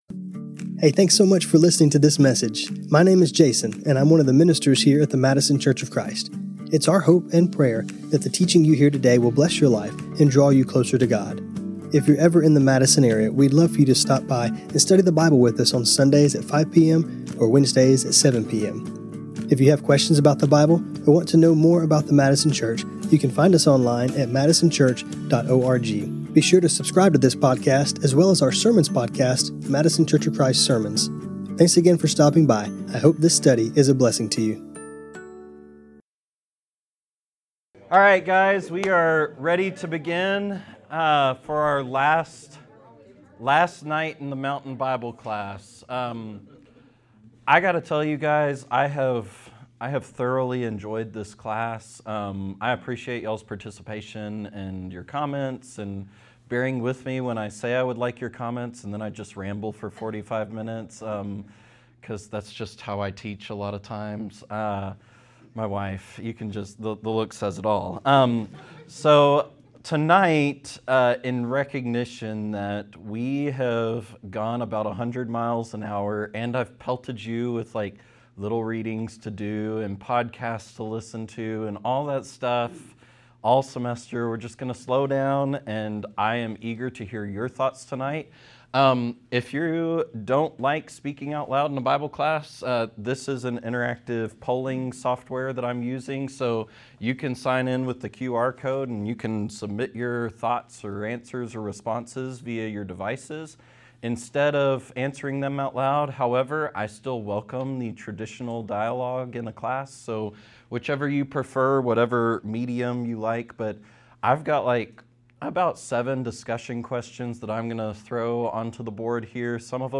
This class was recorded on Jan 28, 2026 Check out the church archives including notes for this class Find us on Facebook.